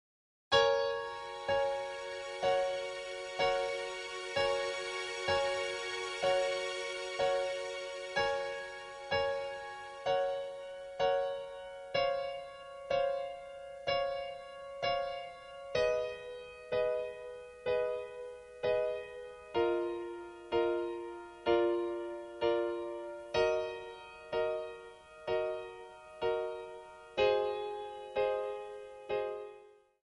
Backing track files: Pop (6706)
Buy With Backing Vocals.